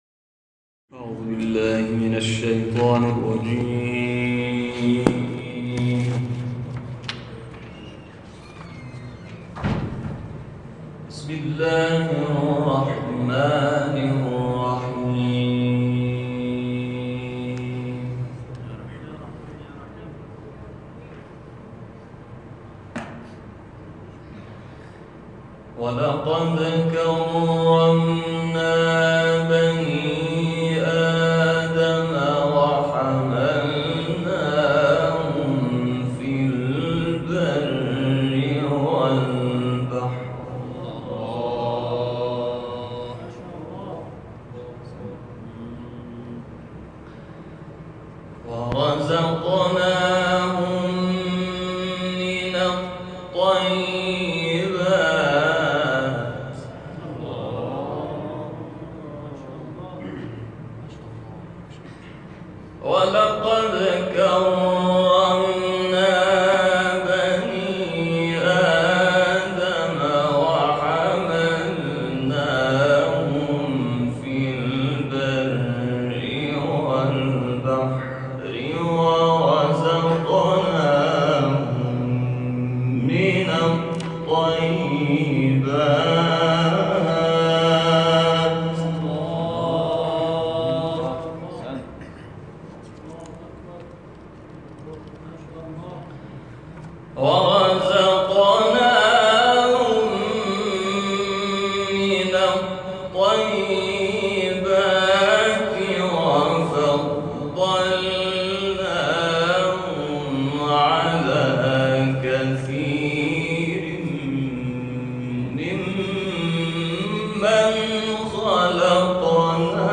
تلاوت «حامد ولی‌زاده» از سوره اسراء
گروه شبکه اجتماعی: تلاوت آیاتی از سوره مبارکه اسراء با صدای حامد ولی‌زاده را می‌شنوید.